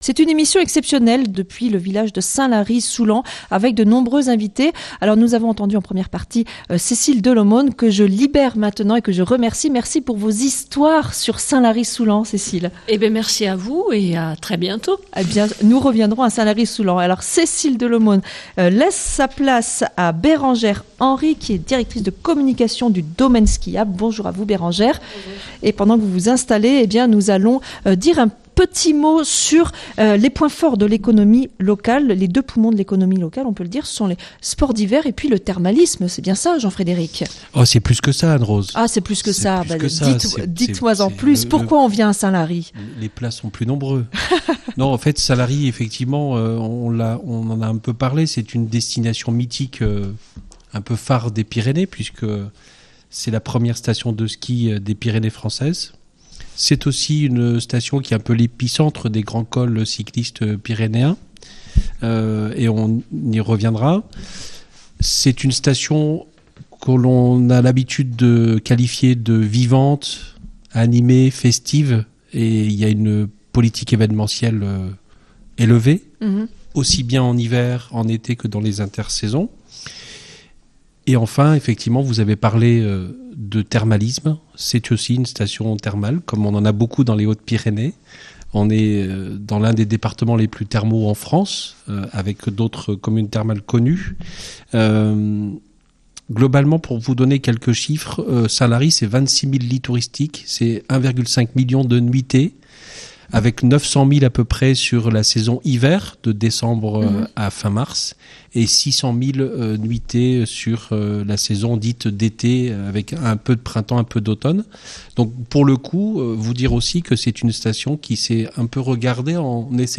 Direct St Lary Partie B